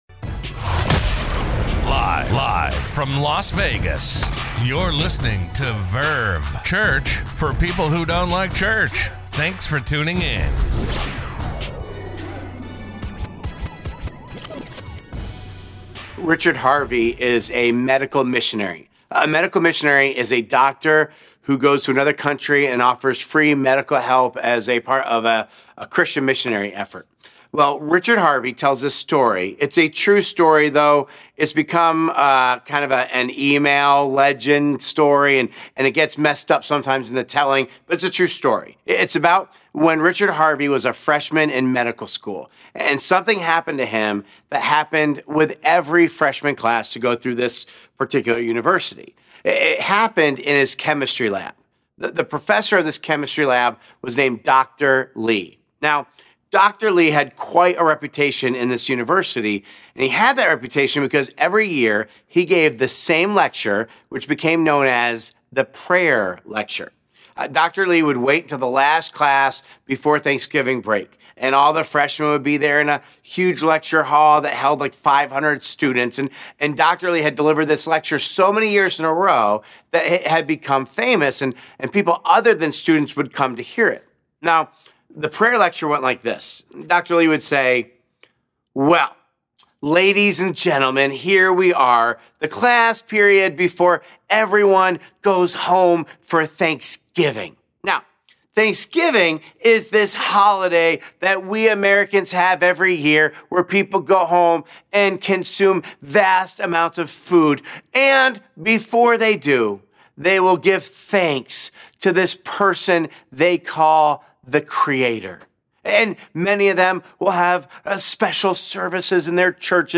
A message from the series "Saved By The Bell ."